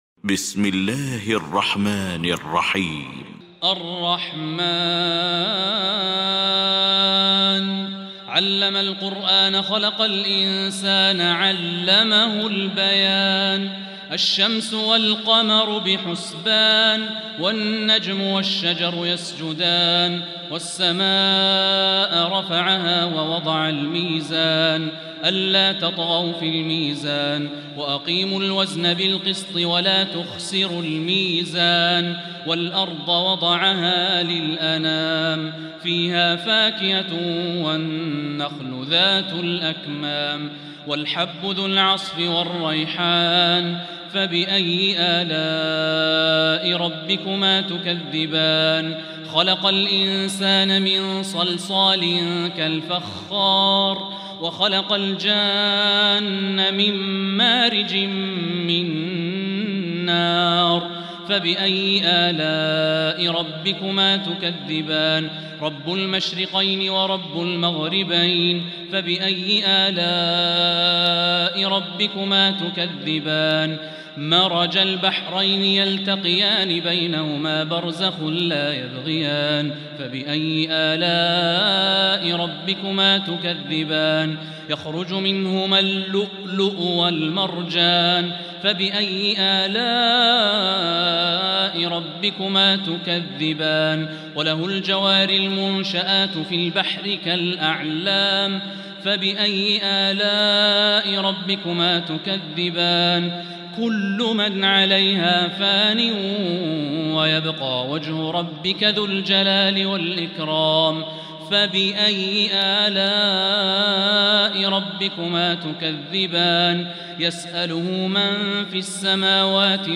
سورة الرحمن | تراويح الحرم المكي عام 1445هـ > السور المكتملة للشيخ الوليد الشمسان من الحرم المكي 🕋 > السور المكتملة 🕋 > المزيد - تلاوات الحرمين